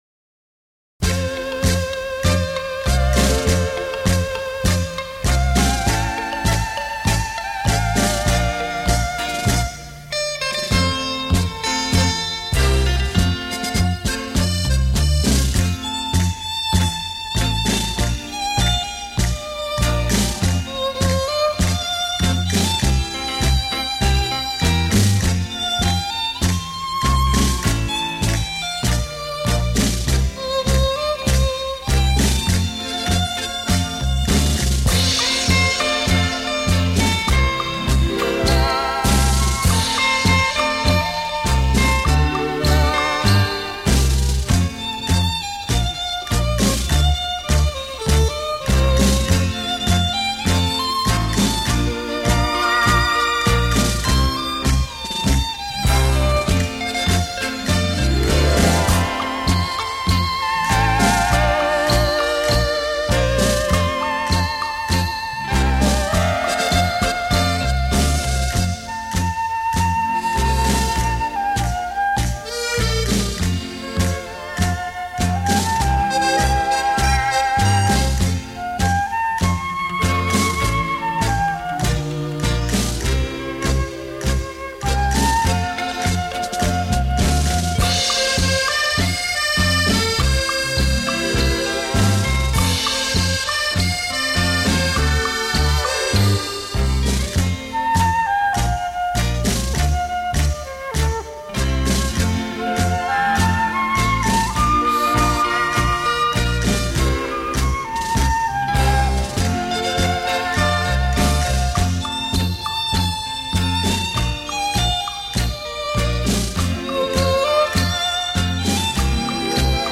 全球期待，收录48首探戈音乐，突破以往、再次创造管弦乐的全新纪元
探戈04